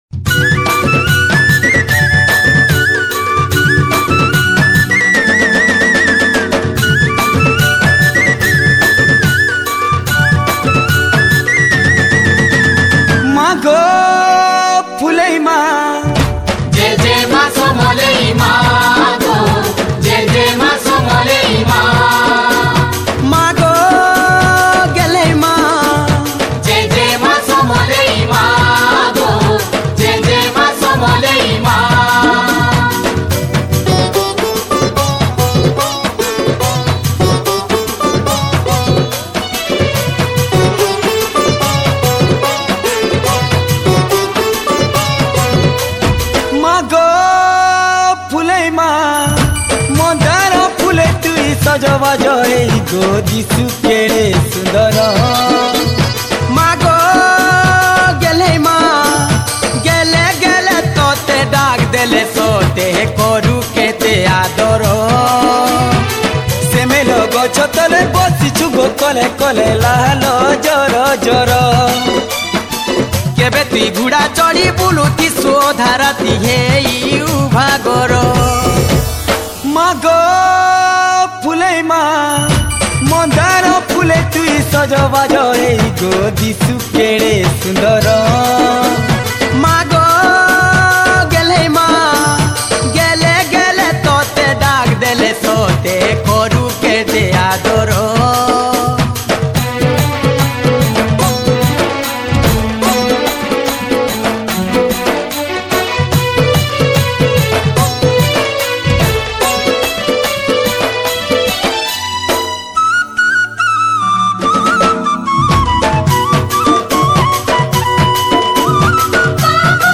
Category: Maa (Sambalpuri Bhajan)